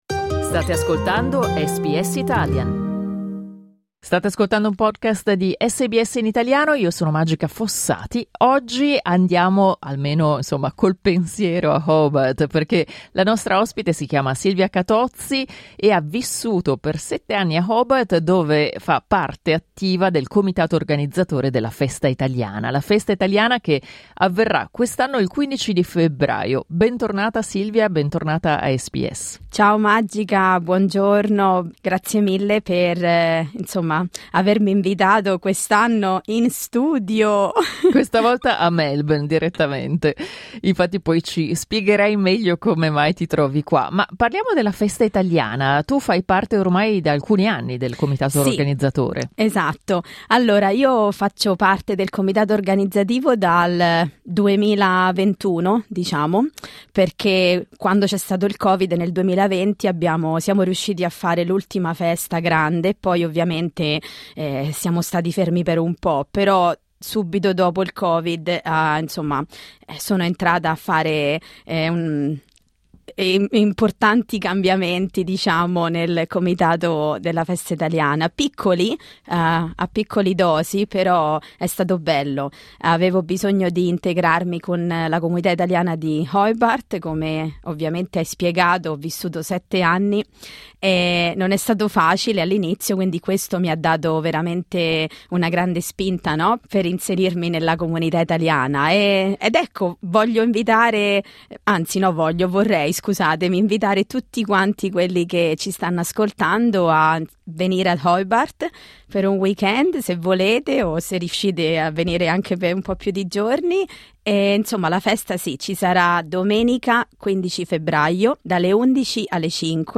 Clicca sul tasto "play" in alto per ascoltare l'intervista integrale